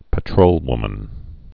(pə-trōlwmən)